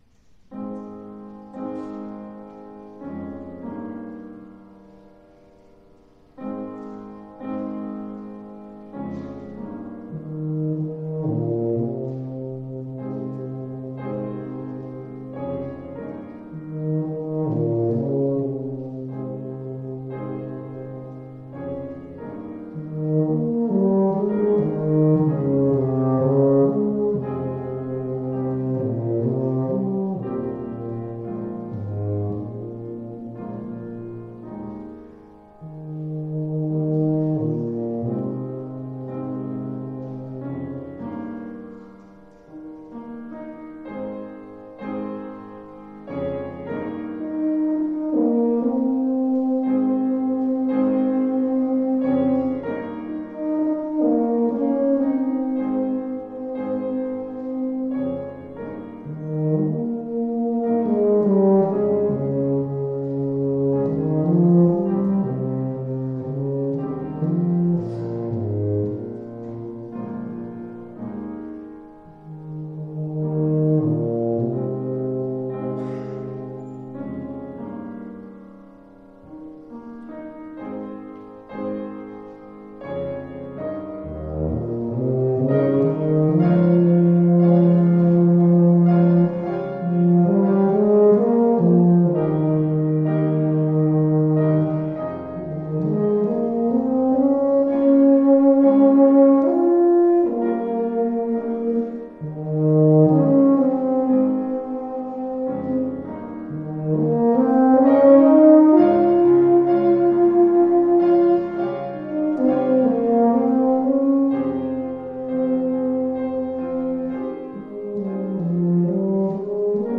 Voicing: Tuba Solo